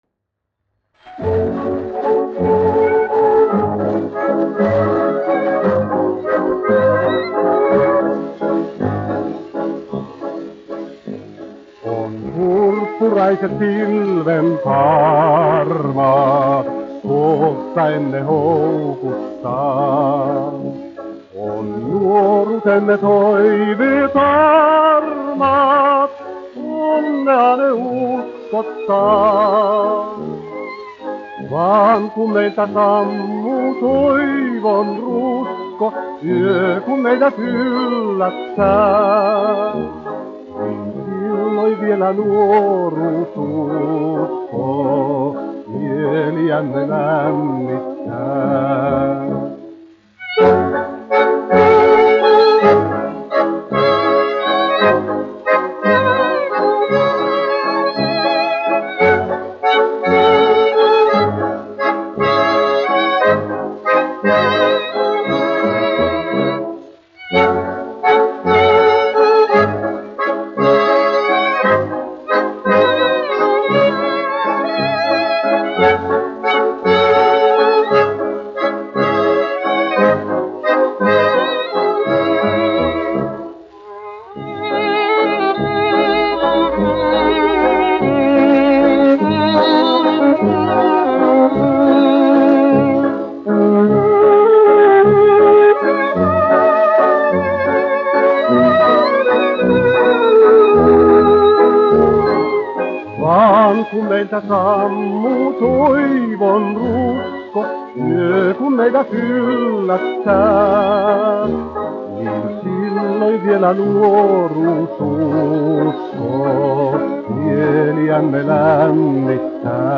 1 skpl. : analogs, 78 apgr/min, mono ; 25 cm
Valši
Populārā mūzika--Somija
Skaņuplate